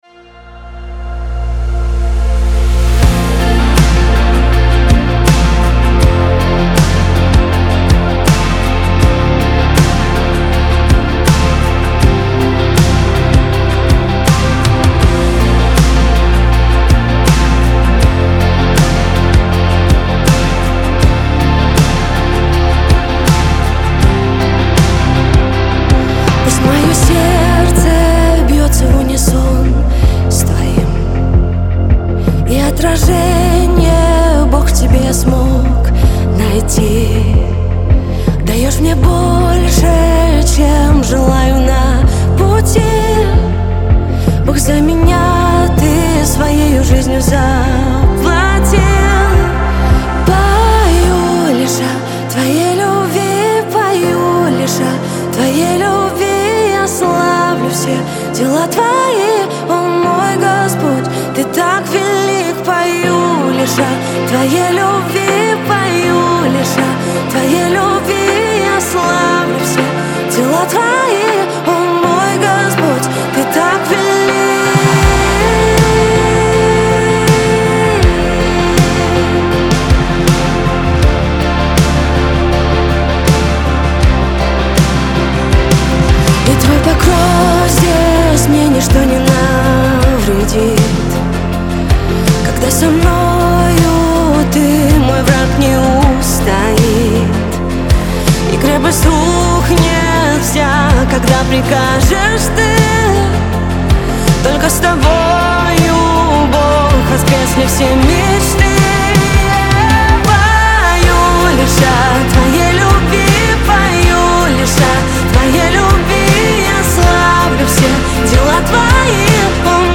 песня
184 просмотра 642 прослушивания 418 скачиваний BPM: 160